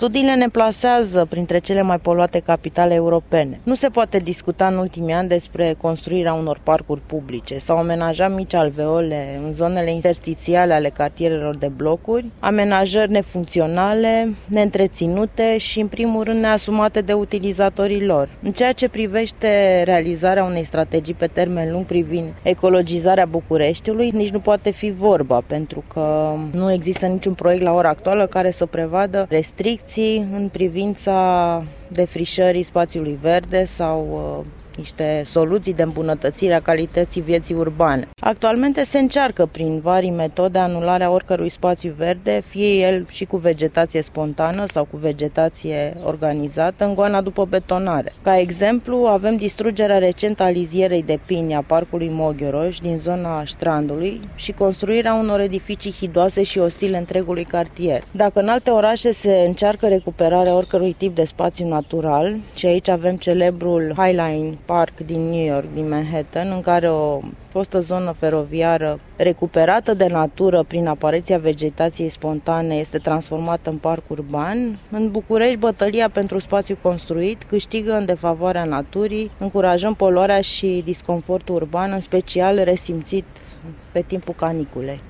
doctor în urbanism